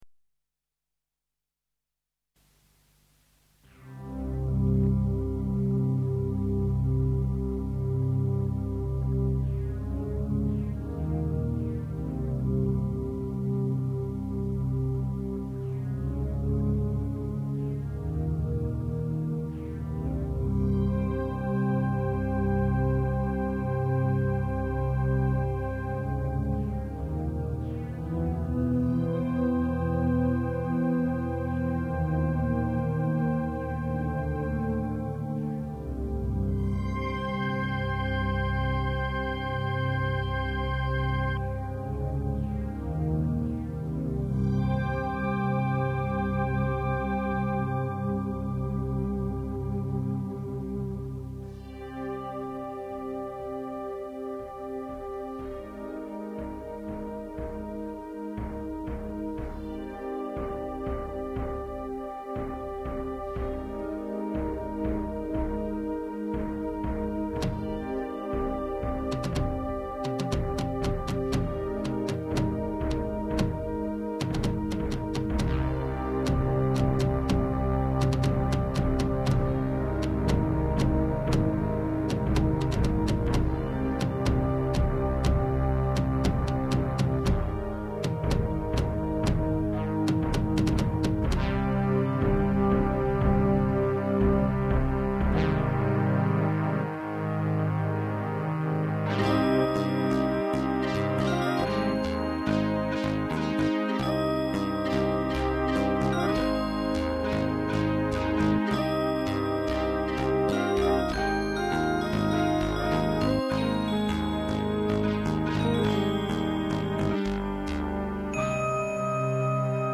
The second piece, which is better in my opinion, is called Fragments, and is a much more gentle and ethereal piece of music, composed in 1997.
It was composed on a PC running Logic on Windows 95, and the sounds are produced by a Roland D-5 keyboard.